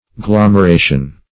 Search Result for " glomeration" : The Collaborative International Dictionary of English v.0.48: Glomeration \Glom`er*a"tion\, n. [L. glomeratio.]